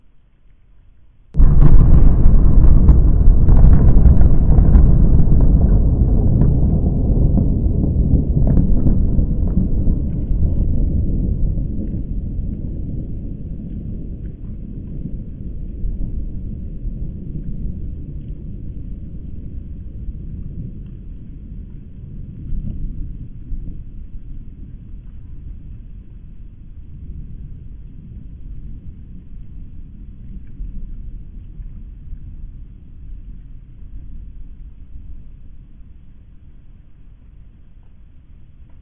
На этой странице представлены записи звуков атомного взрыва — мощные, устрашающие и заставляющие задуматься о хрупкости мира.
Звук настоящего ядерного взрыва